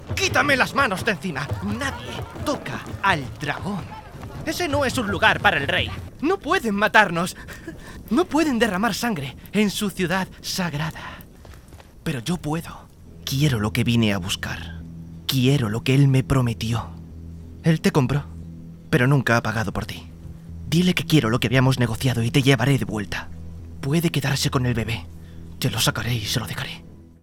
Spanish speaker, voice over, young voice, voice actor, station voice.
kastilisch
Sprechprobe: Sonstiges (Muttersprache):